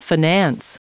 Prononciation du mot finance en anglais (fichier audio)
Prononciation du mot : finance